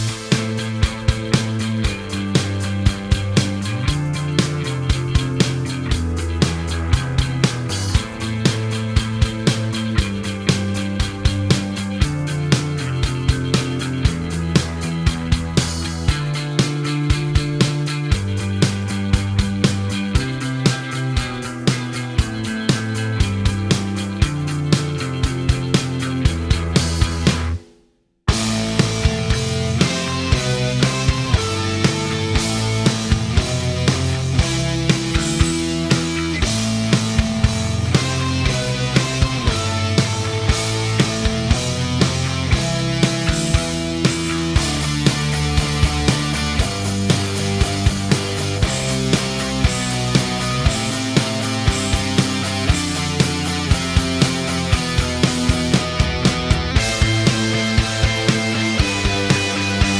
Minus Main Guitars For Guitarists 3:08 Buy £1.50